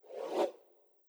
SwooshSlide1a.wav